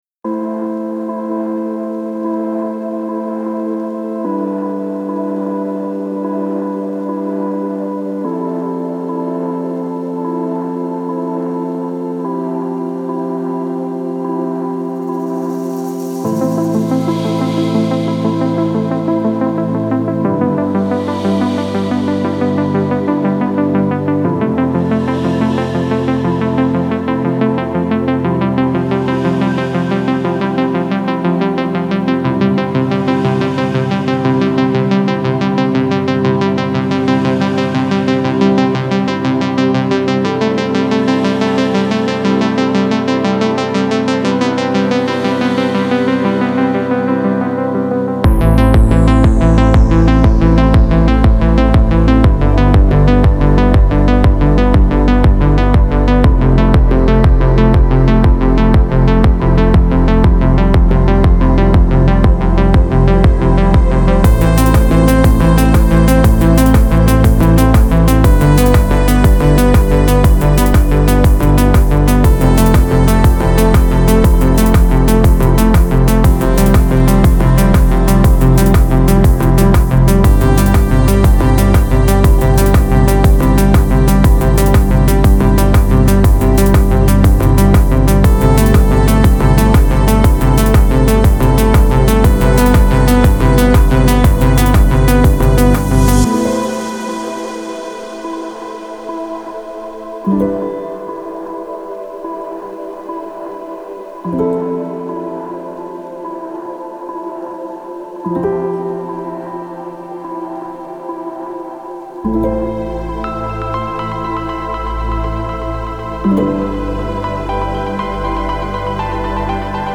امید‌بخش , پر‌انرژی , رقص , موسیقی بی کلام